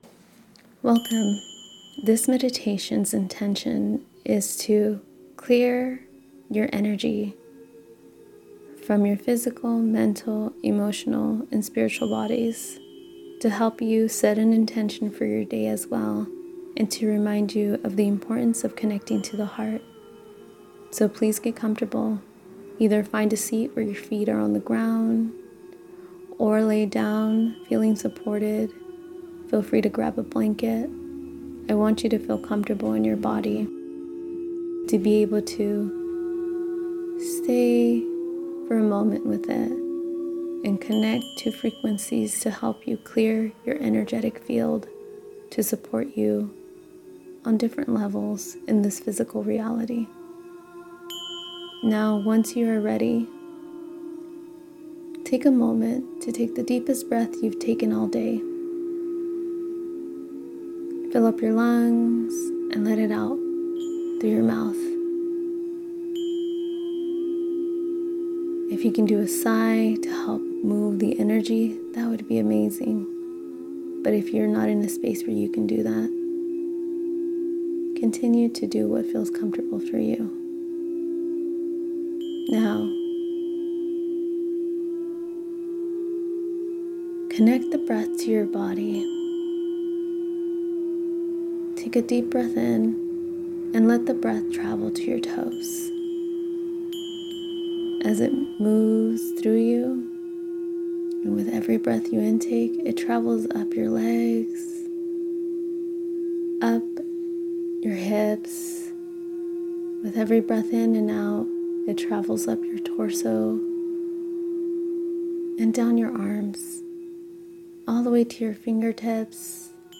This is a 16 minute meditation is designed to help cleanse your four energy bodies, connect you to your heart, & your soul's light energy. This is a great meditation to help you set an intention for your day and welcome in guidance and support from Spirit.
channel-of-light-clearing-meditation-IWLSXMepq9DC6kC3.mp3